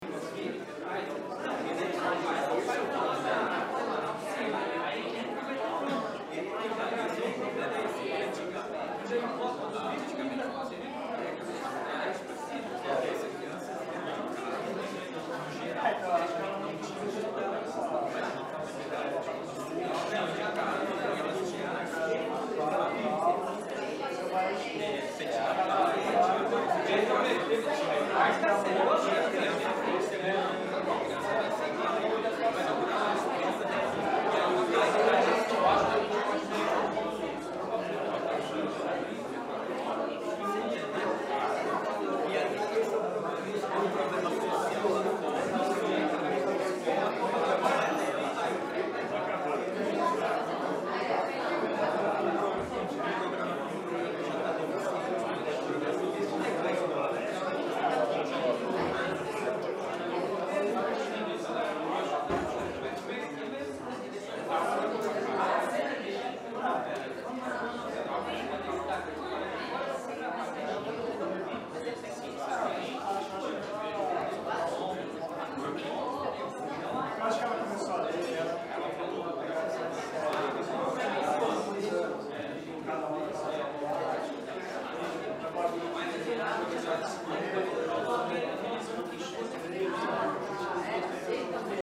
Люди в аудитории разговаривают